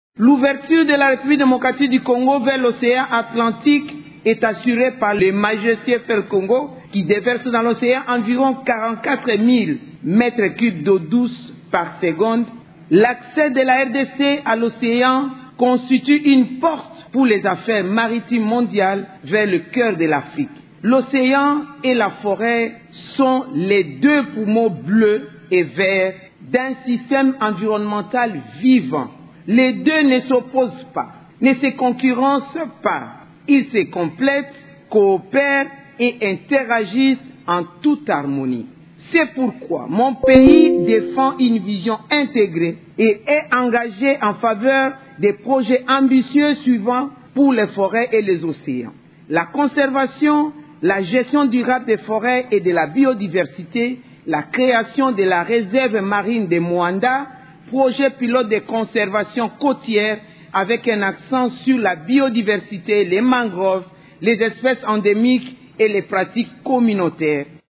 La ministre d’Etat congolaise chargée de l’Environnement, Eve Bazaiba l’a affirmé du haut de la tribune de la 3ème conférence des Nations unies sur l’océan, qui se tient à Nice en France.